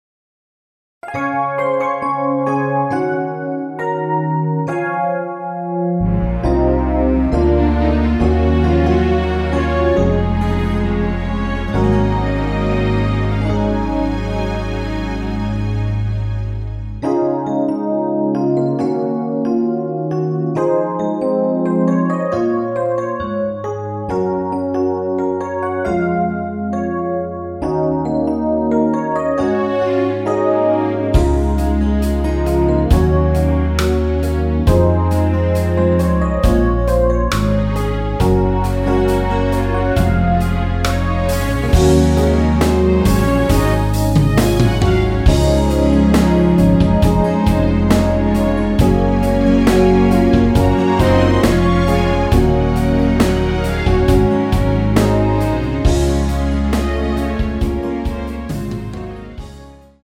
Db
◈ 곡명 옆 (-1)은 반음 내림, (+1)은 반음 올림 입니다.
앞부분30초, 뒷부분30초씩 편집해서 올려 드리고 있습니다.